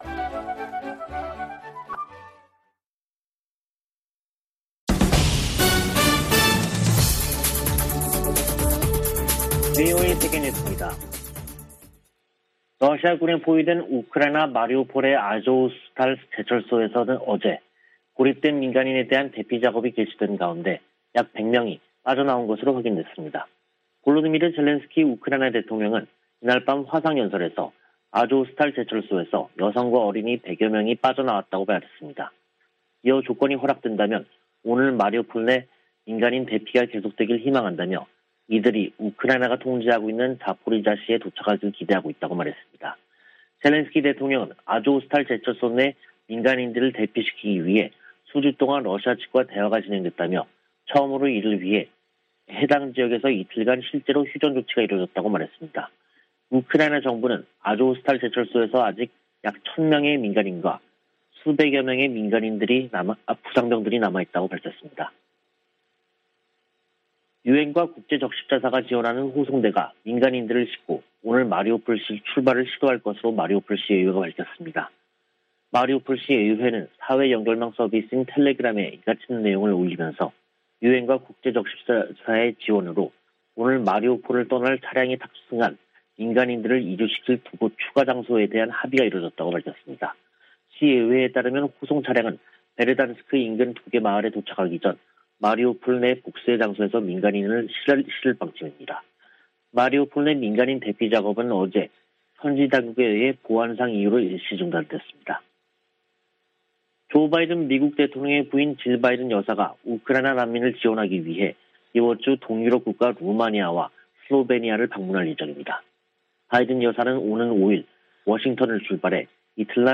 VOA 한국어 간판 뉴스 프로그램 '뉴스 투데이', 2022년 5월 2일 2부 방송입니다. 미 국무부가 핵실험 준비 동향이 포착된 북한에 대해 역내에 심각한 불안정을 초래한다고 지적하고 대화를 촉구했습니다. 북한 풍계리 핵실험장 3번 갱도 내부와 새 입구 주변에서 공사가 활발히 진행 중이라는 위성사진 분석이 나왔습니다.